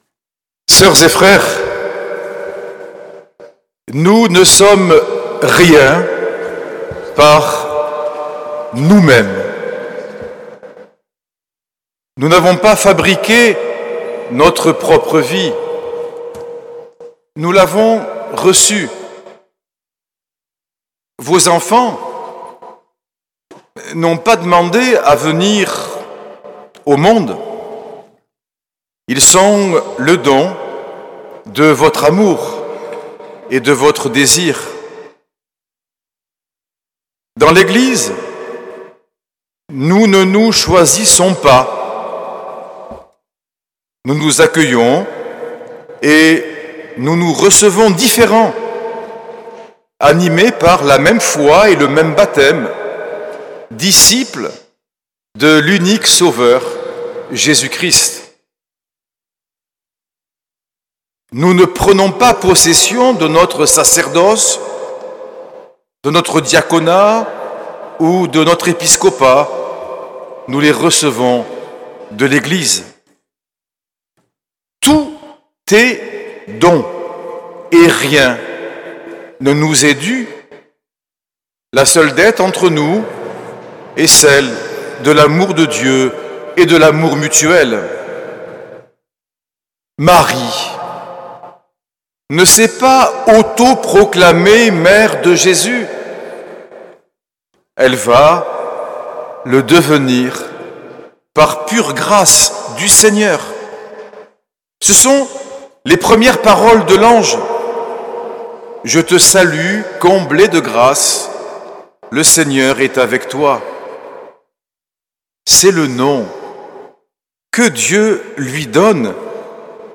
L’enregistrement retransmet l’homélie de Monseigneur Norbert TURINI.